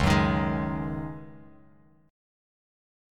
C#sus2sus4 chord